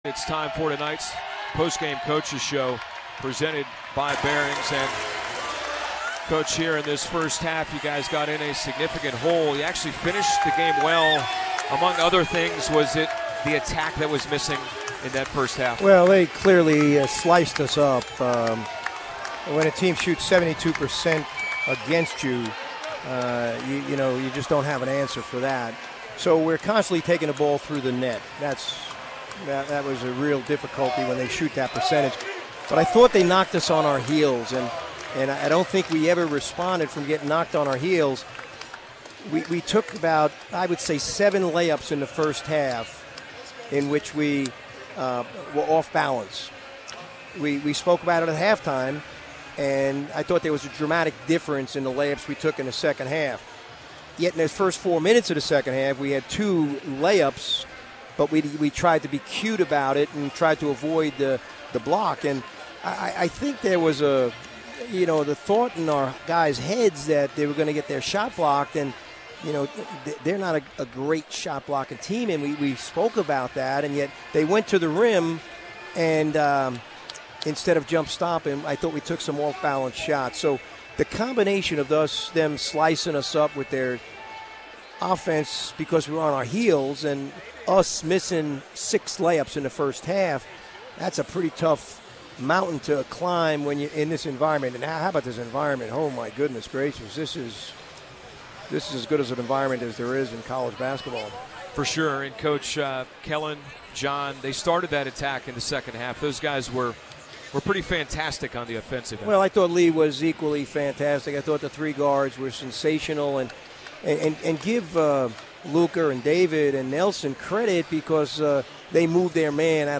McKillop Postgame Radio Interview